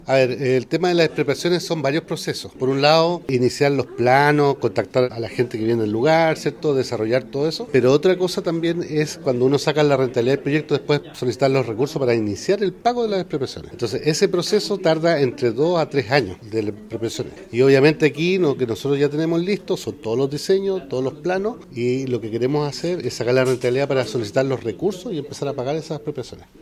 En cuanto a las expropiaciones necesarias para avanzar con los trabajos, el director regional de Vialidad, Jorge Loncomilla, entregó mayores detalles.